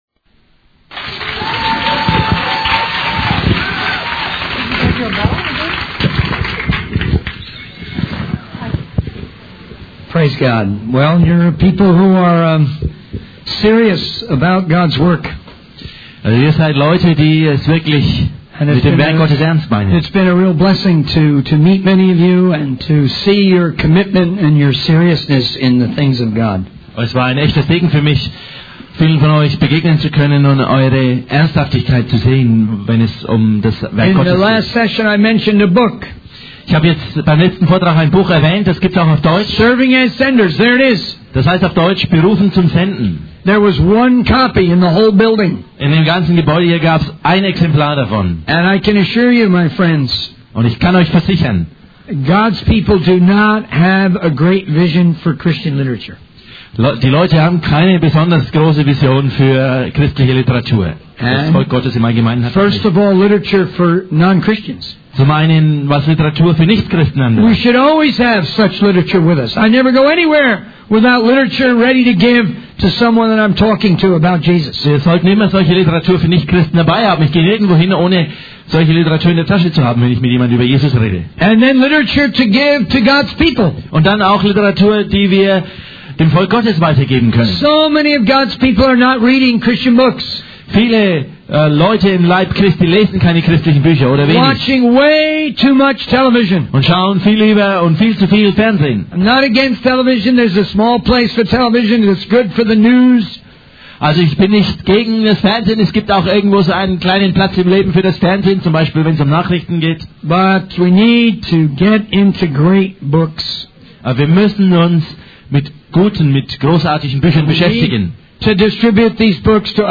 In this sermon, the speaker emphasizes the importance of reading great books and distributing them to others. He shares an example of a church that made a particular book required reading for their missions committee.